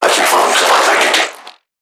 NPC_Creatures_Vocalisations_Infected [51].wav